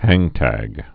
(hăngtăg)